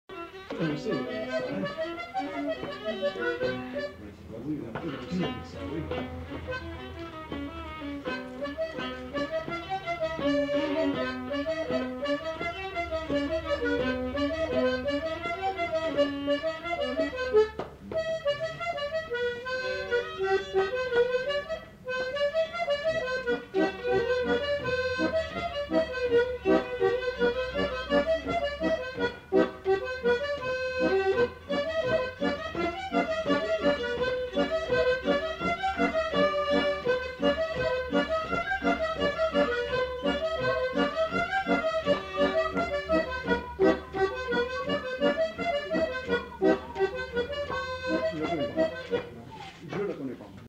Scottish
Aire culturelle : Agenais
Lieu : Foulayronnes
Genre : morceau instrumental
Instrument de musique : accordéon diatonique ; violon
Danse : scottish
Ecouter-voir : archives sonores en ligne